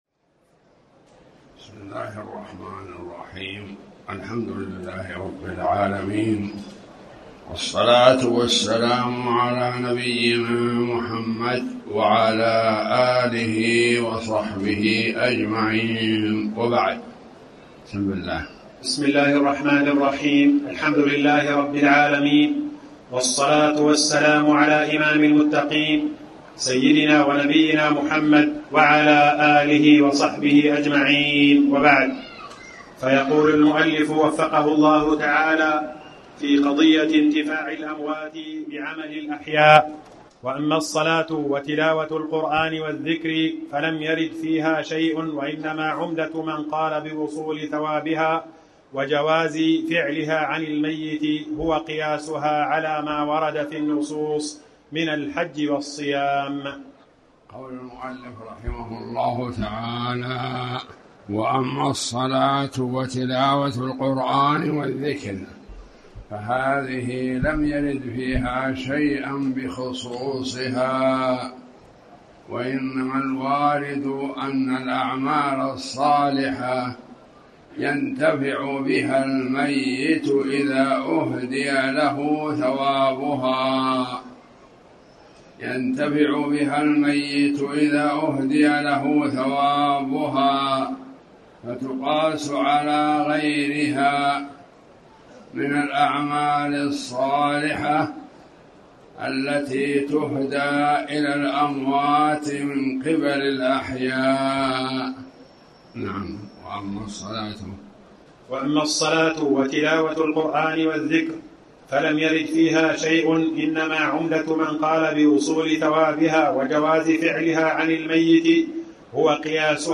تاريخ النشر ٢٩ ربيع الثاني ١٤٣٩ هـ المكان: المسجد الحرام الشيخ